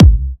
VEC3 Bassdrums Trance 27.wav